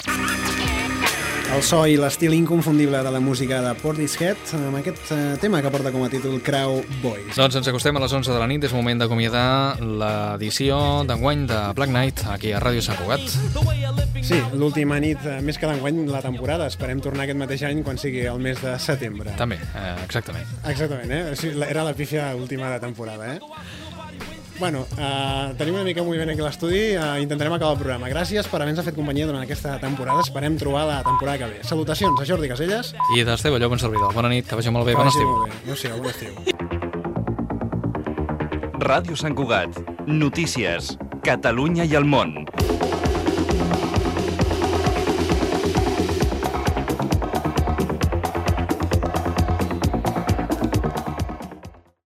Comiat en l'última edició del programa i careta del butlletí de notícies
Musical
FM